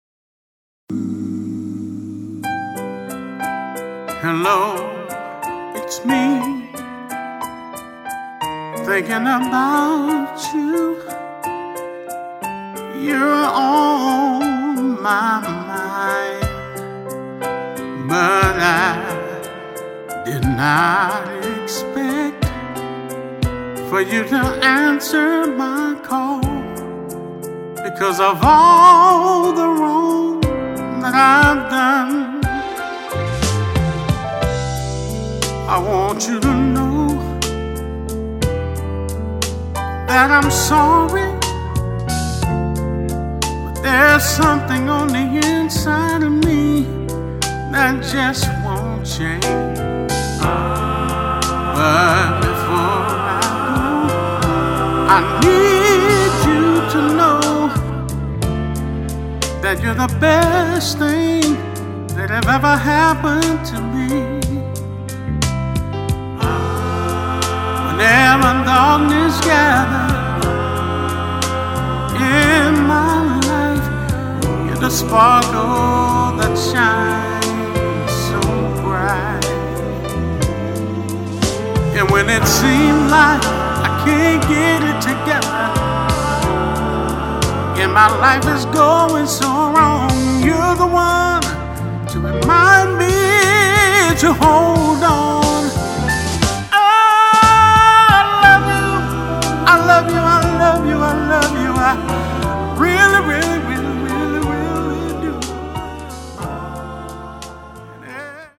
R&B 70s STYLE